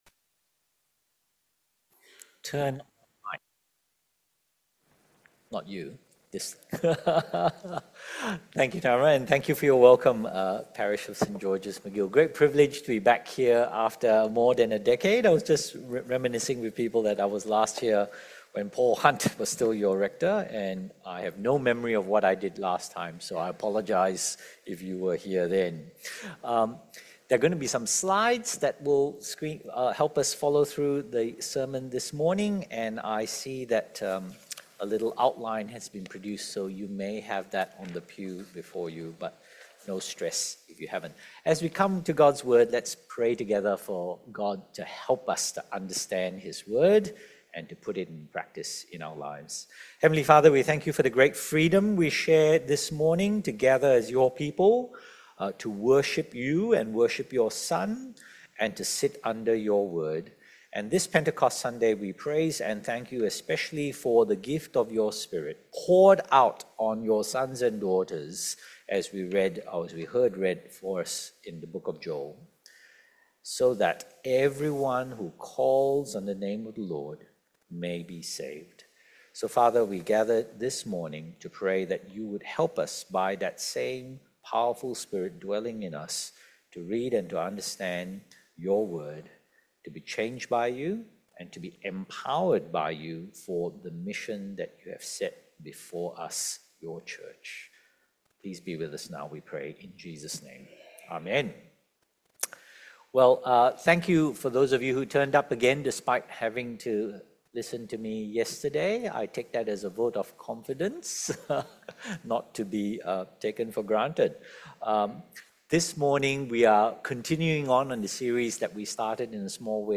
Sermons | St George's Magill Anglican Church